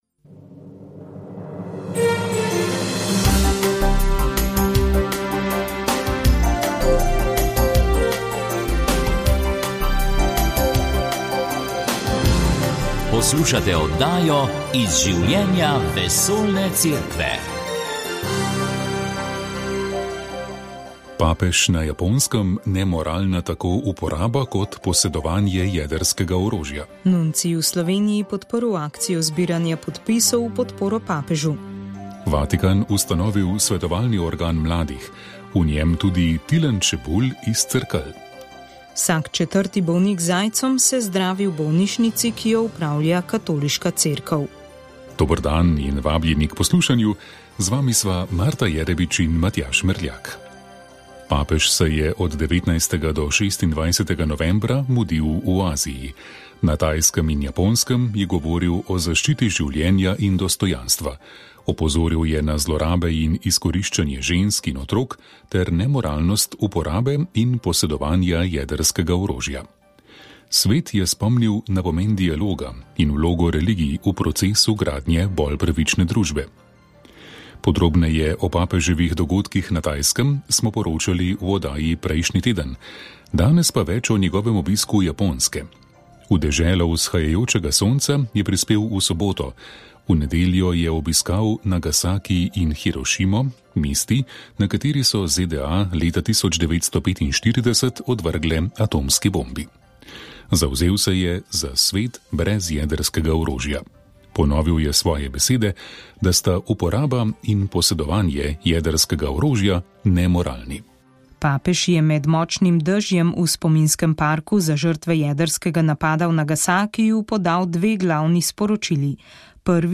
V oddaji ste slišali, kdaj bo za svetnika razglašen Carlo Acutis in kako želi biti pokopan papež Frančišek. Vatikan je namreč predstavil prenovljen bogoslužni obrednik papeževega pogreba. V daljšem pogovoru pa ste lahko prisluhnili